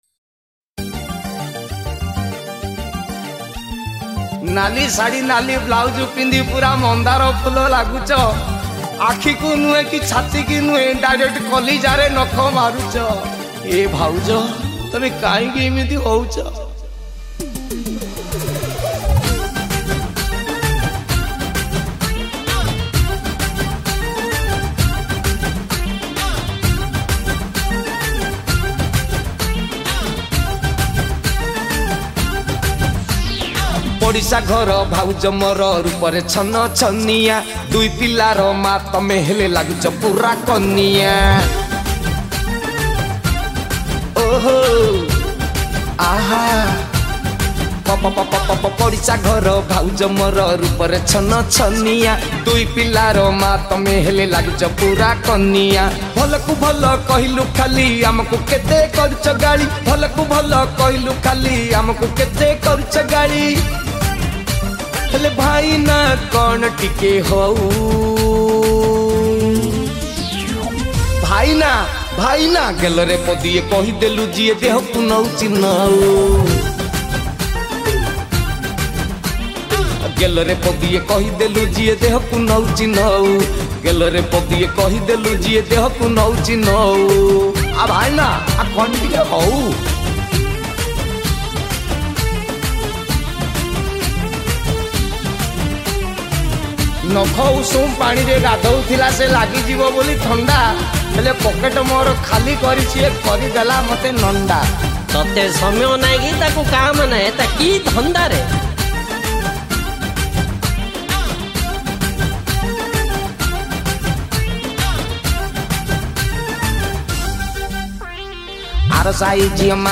{studio version}